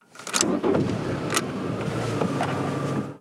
Llave de arranque de un coche Golf
Sonidos: Transportes